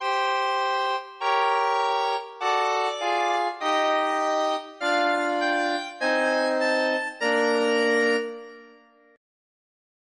Notem no original, do início da Primeira Sinfonia de Brahms, os deslocamentos da voz superior, o pedal, e novamente a impossibilidade de uma análise harmônica.